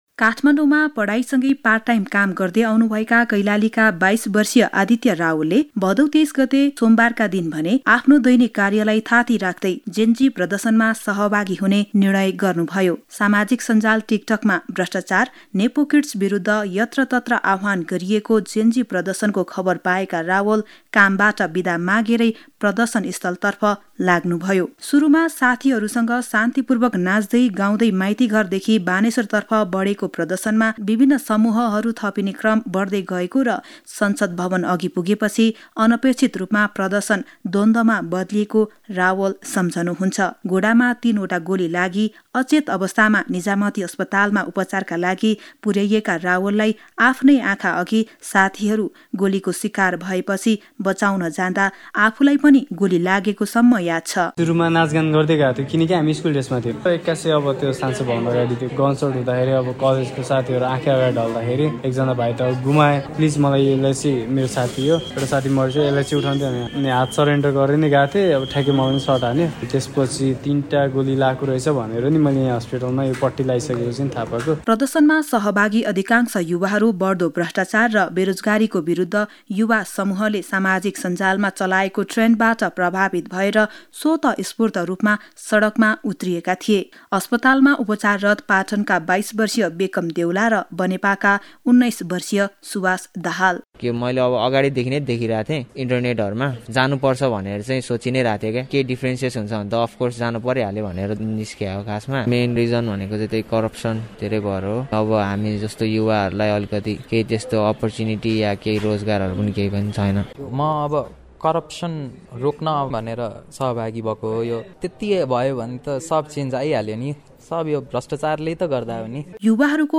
यसो भन्छन् जेनजी प्रर्दशनका घाइतेहरु (अडियो रिपार्ट)